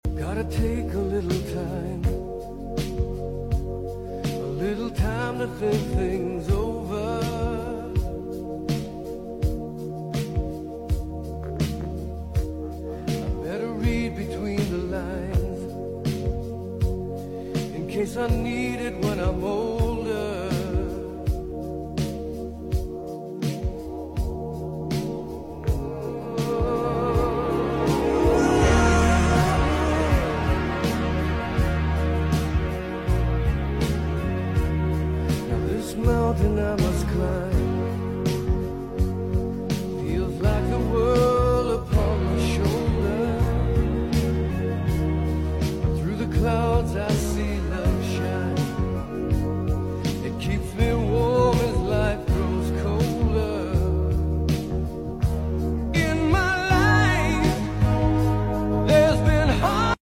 is live at farm and 1975